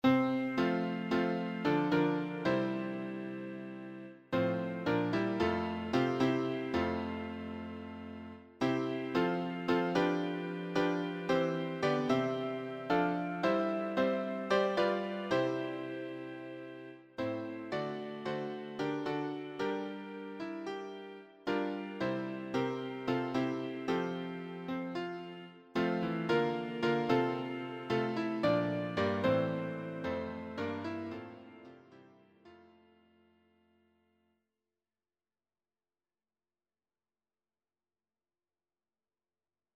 choir SATB
Electronically Generated